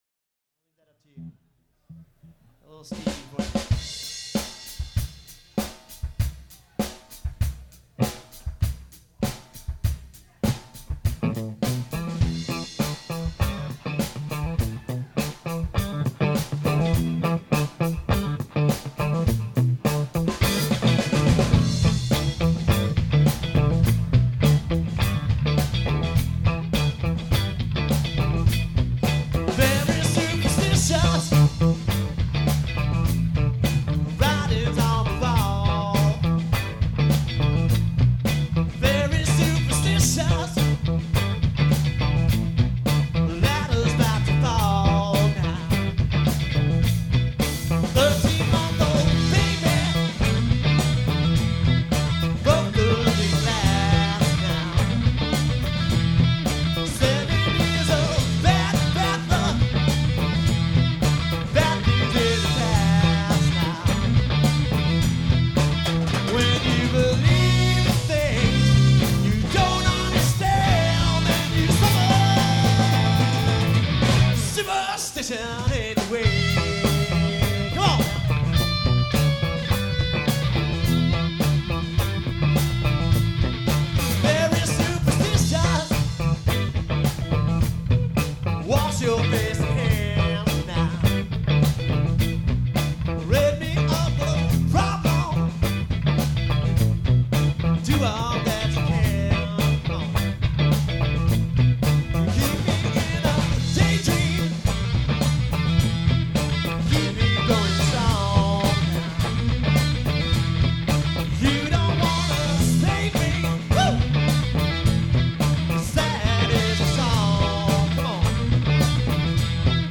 Recorded at Dargan’s Pub, Santa Barbara 2004.